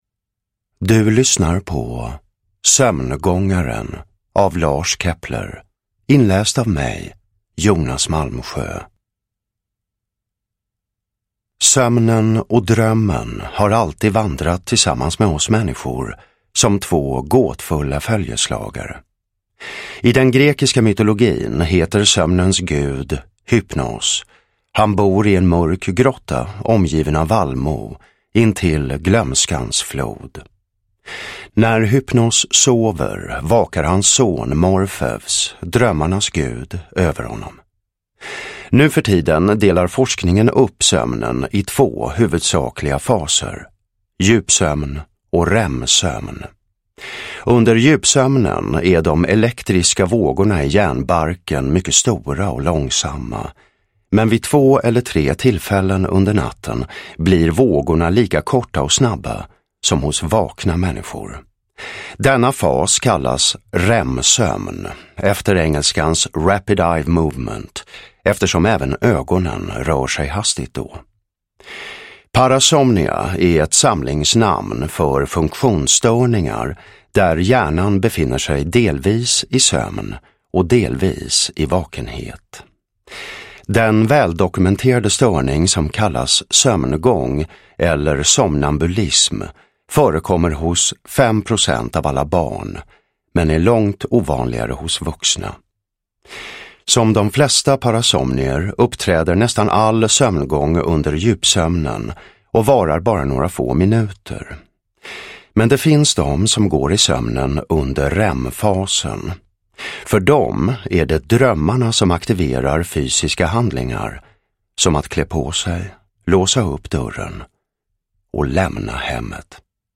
Sömngångaren (ljudbok) av Lars Kepler